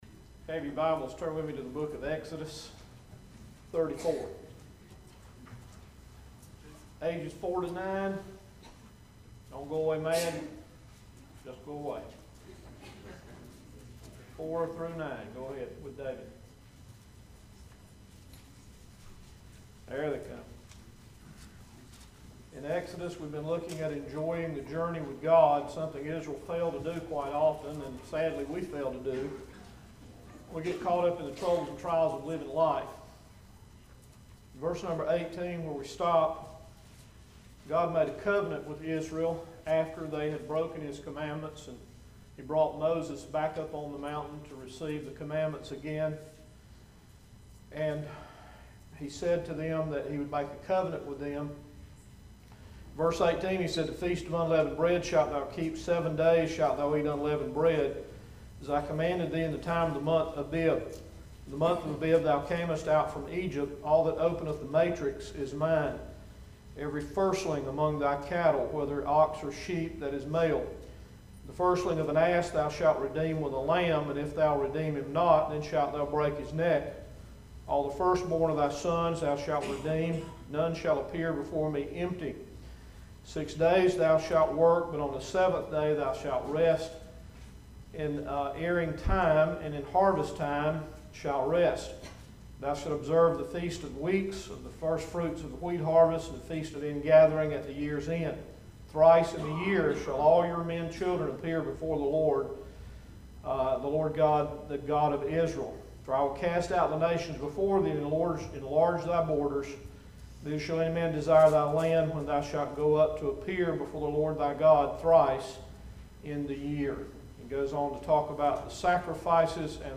7-20-14-PM-Servicemessage.mp3